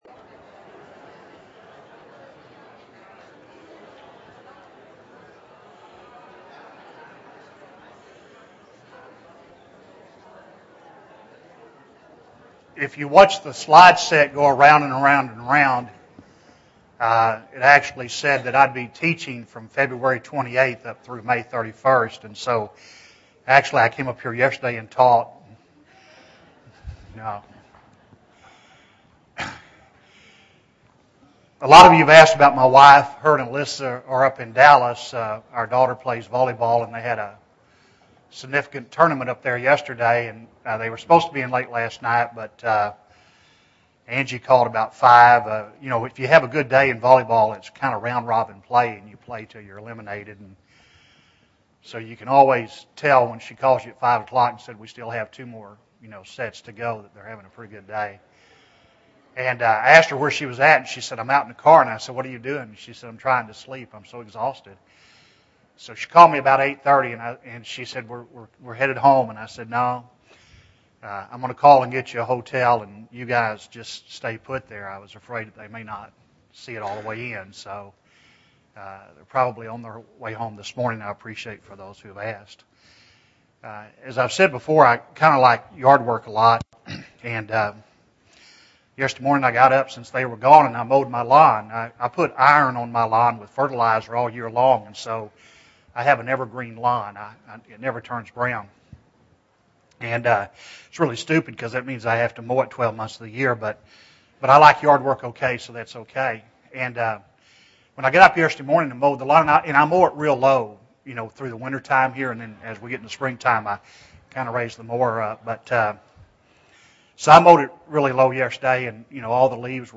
Joshua 1 (1 of 14) – Bible Lesson Recording